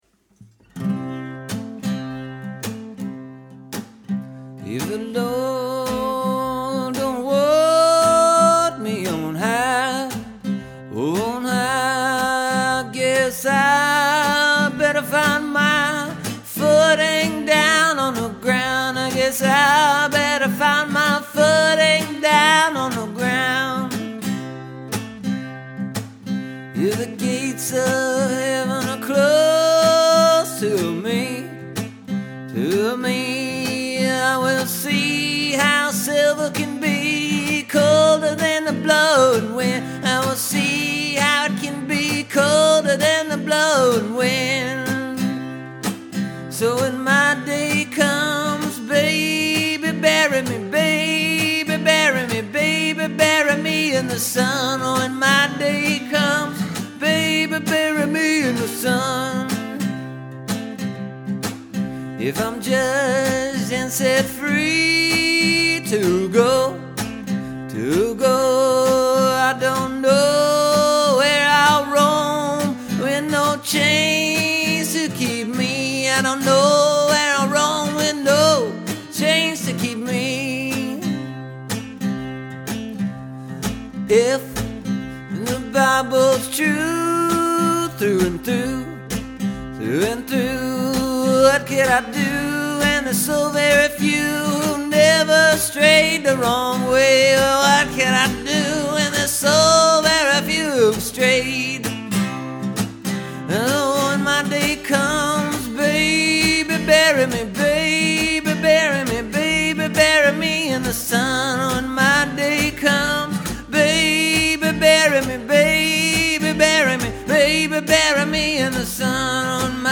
I sang it in a key that hurt my voice, but I think it sounds right to me. I was going to add some other noise to it…clapping and drumming and honking and wheezing, but after I listened to the playback a couple times I decided it already had all that and didn’t need any other tracks.